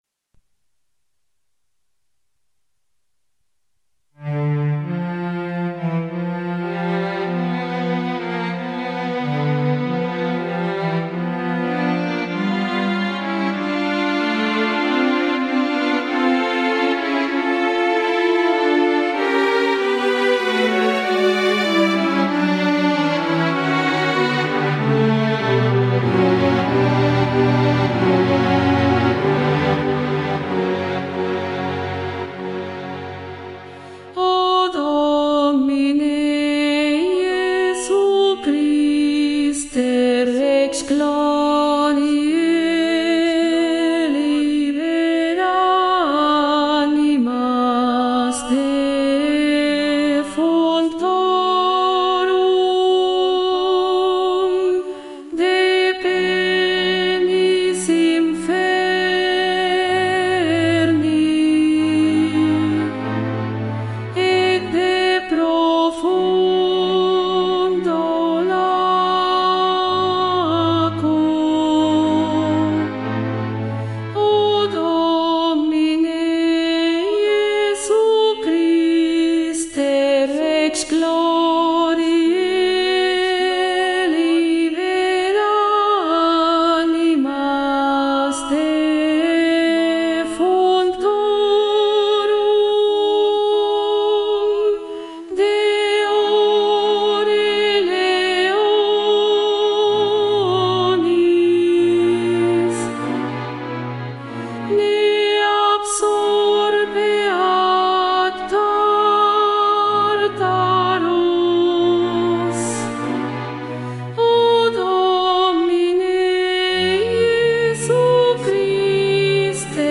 Contralto
Mp3 Profesora
2.-Ofertorio-CONTRALTO-VOZ.mp3